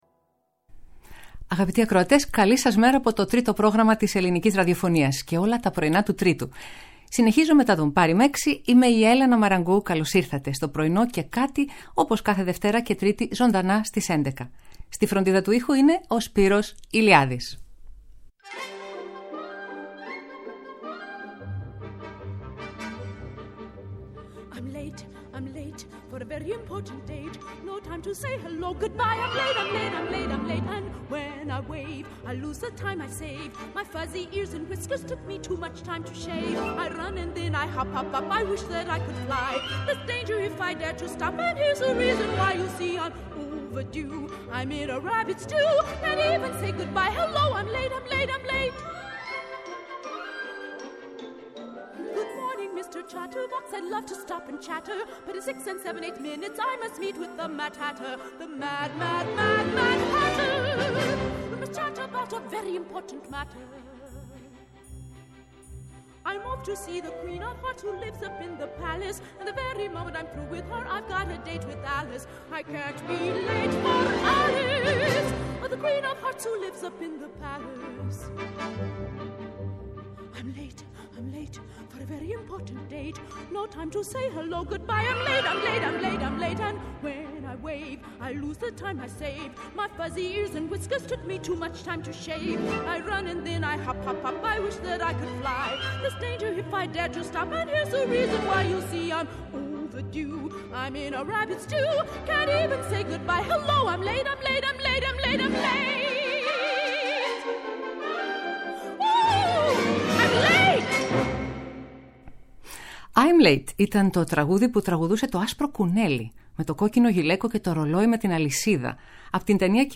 Ερμηνείες από όλες τις εποχές και μερικές ανατροπές συνοδεύουν τις μικρές και μεγάλες εικόνες της ημέρας.